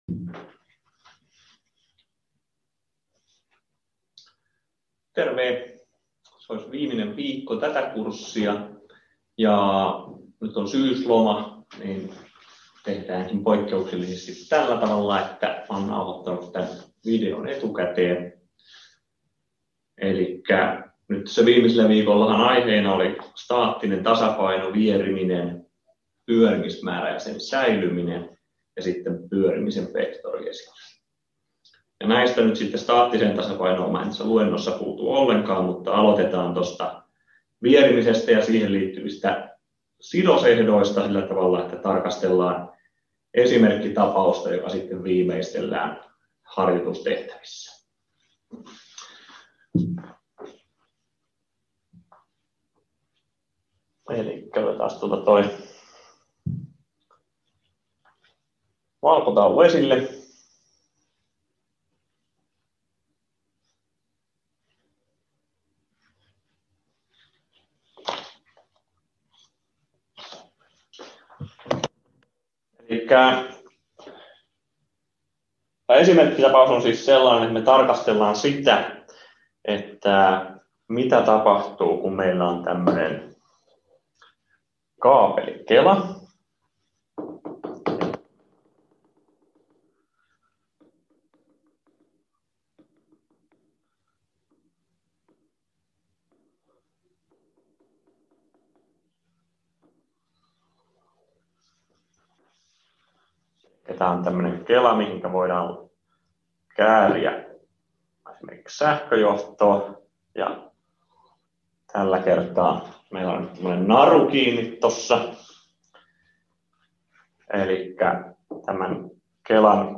FYSP1010, Luento 7 — Moniviestin